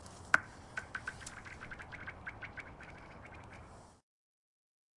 描述：this sample is part of the icefieldlibrary. i used a pair of soundman okm2 mics as contact microphones which i fixed to the surface of a frozen lake, then recorded the sounds made by throwing or skimming several stones and pebbles across the ice. wonderful effects can also be achieved by filtering or timestretching the files.
标签： chill winter crack fieldrecording lake skid cold ice
声道立体声